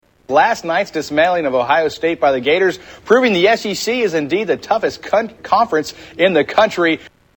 Tags: Media Freudian Slips News Newscasters Funny